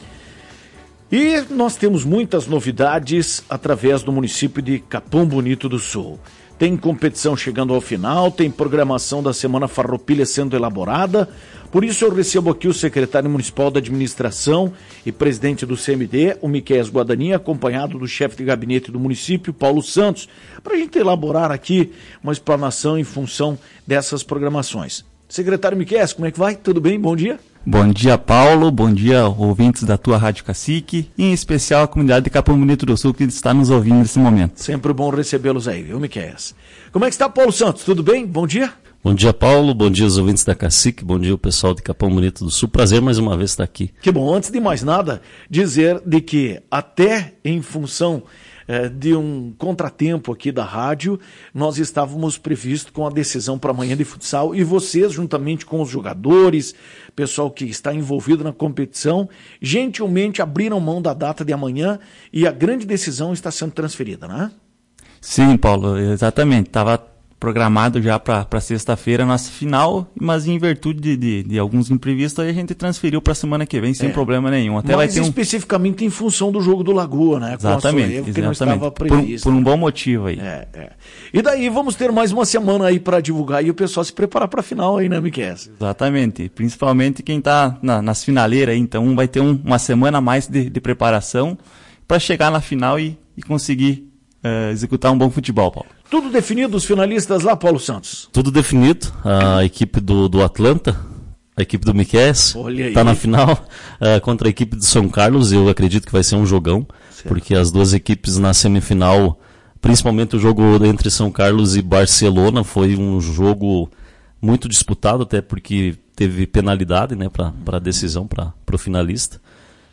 Em entrevista para a Tua Rádio Cacique, o Chefe de Gabinete do Município - Paulo Santos, juntamente do Secretário Municipal da Administração e Presidente do CMD - Miqueias Guadagnin, falaram sobre o andamento da competição, pois a mesma contou com a participação de mais de cem atletas.